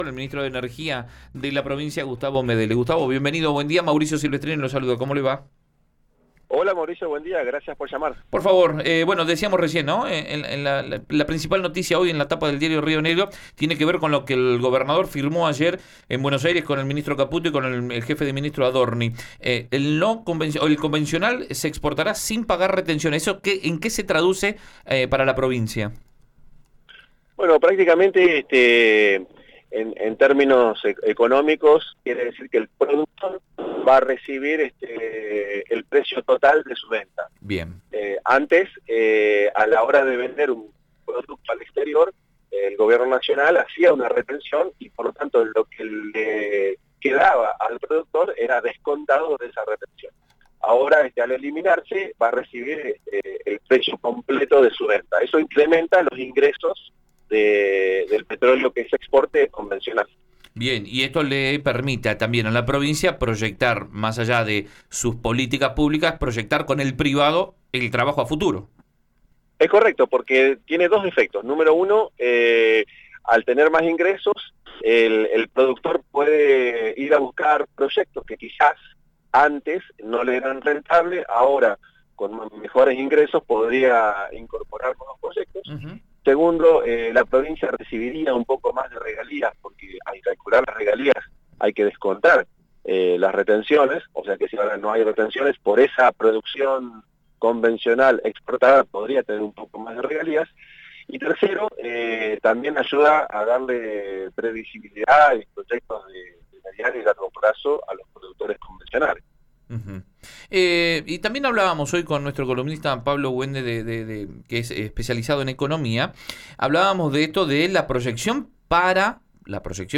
El funcionario explicó a Río Negro Radio que la medida genera un doble efecto positivo en la ecuación económica de las empresas.